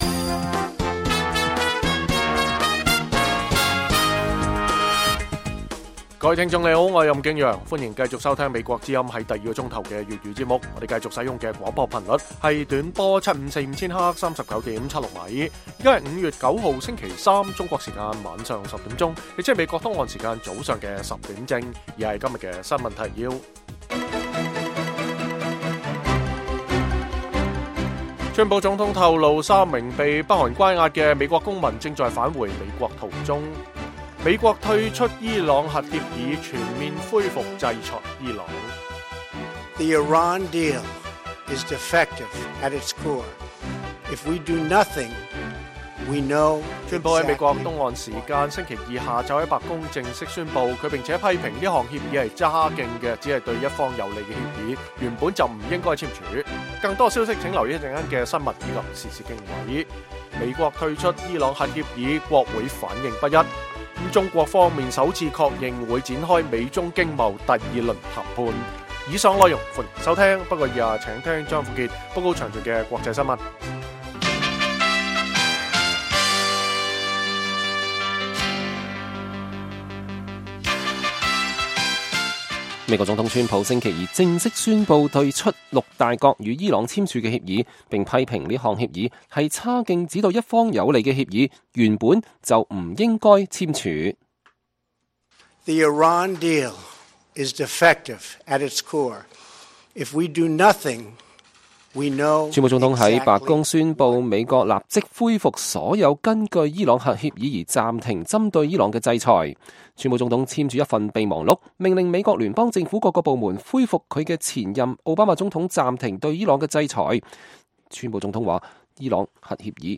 粵語新聞 晚上10-11點
北京時間每晚10－11點 (1400-1500 UTC)粵語廣播節目。內容包括國際新聞、時事經緯和社論。